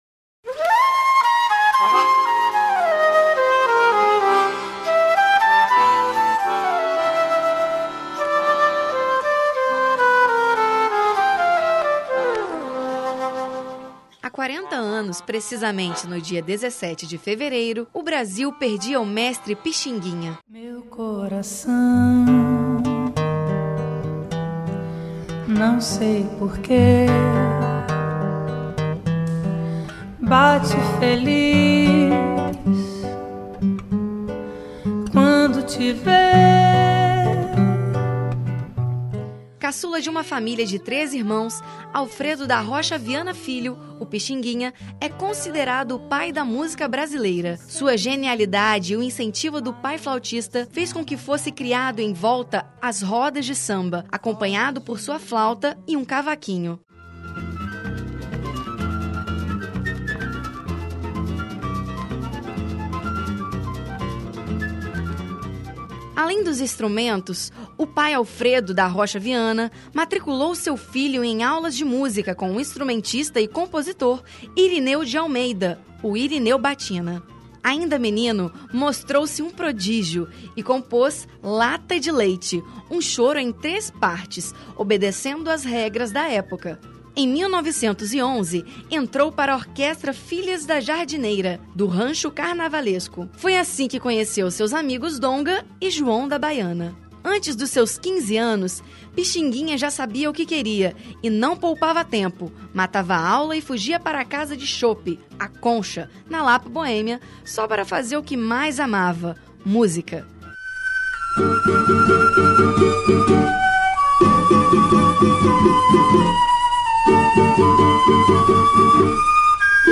Matéria sobre o Pixinguinha Download : Matéria sobre o Pixinguinha